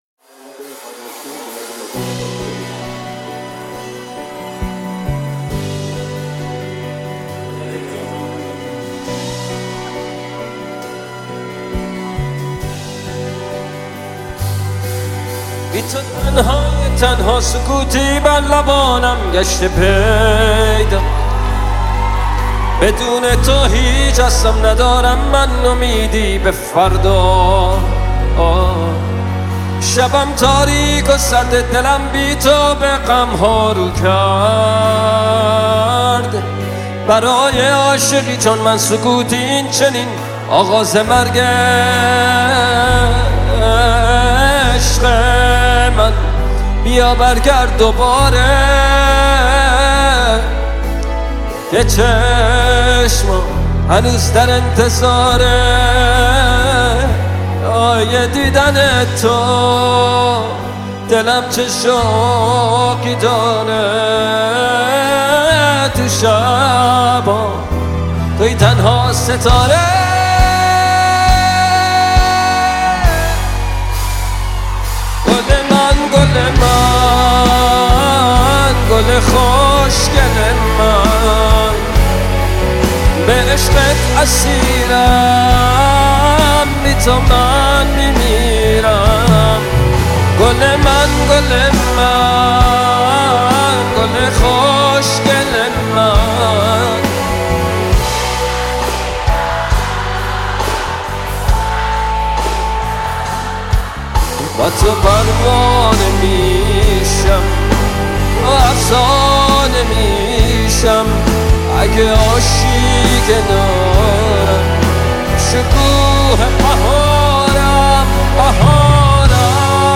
دانلود Live (زنده)